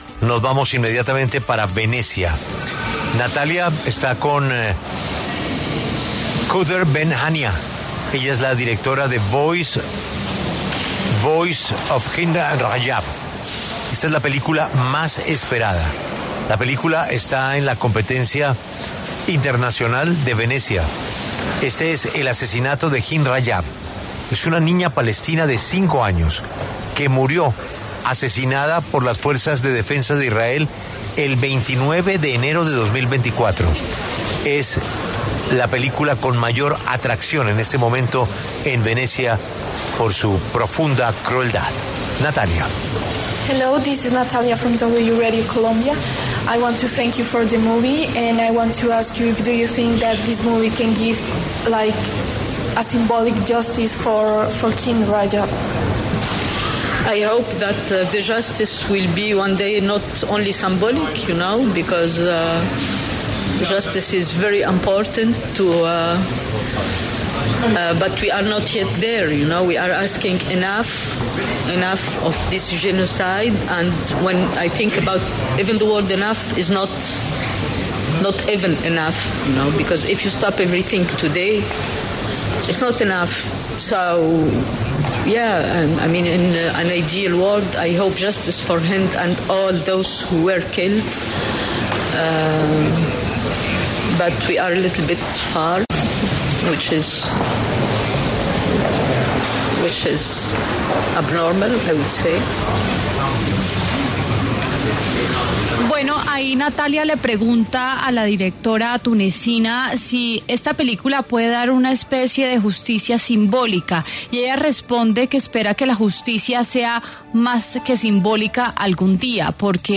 Kaouther Ben Hania, directora de “The Voice Of Hind Rajab”, conversó con La W desde el Festival Internacional de Cine de Venecia.